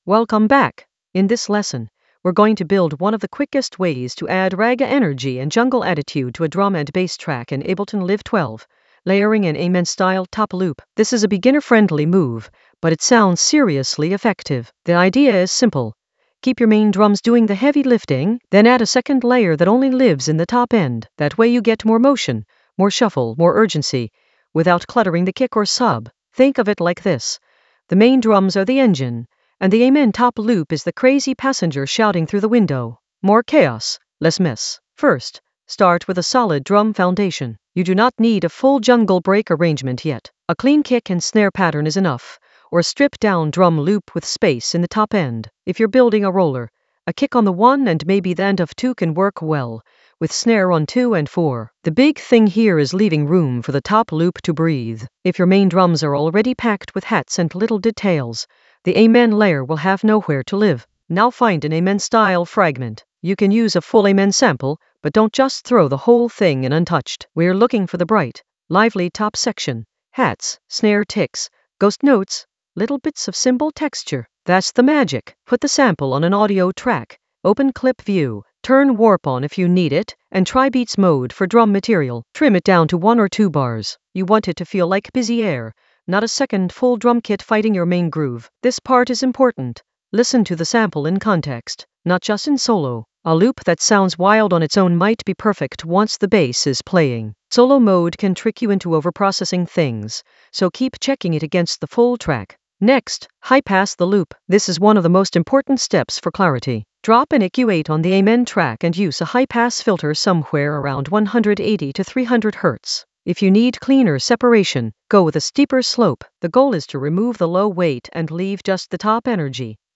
An AI-generated beginner Ableton lesson focused on Layer an Amen-style top loop for ragga-infused chaos in Ableton Live 12 in the FX area of drum and bass production.
Narrated lesson audio
The voice track includes the tutorial plus extra teacher commentary.